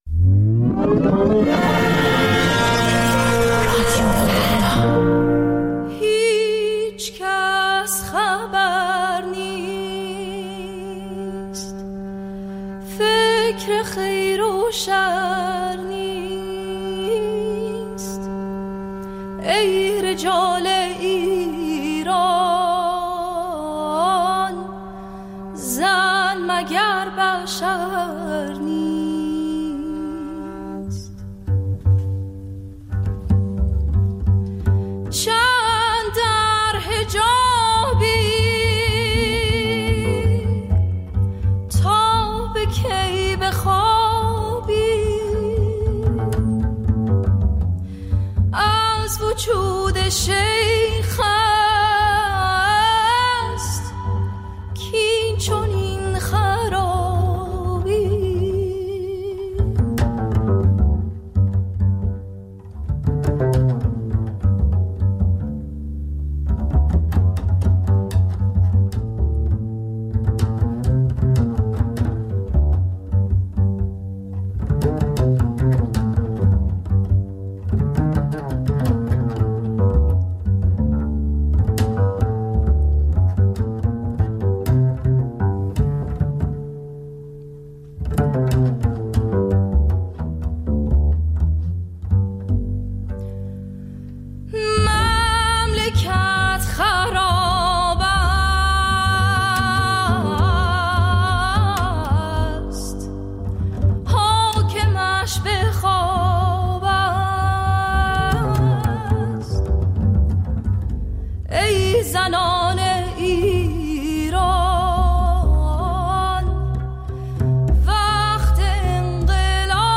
دقایقی با موسیقی جز سول و بلوز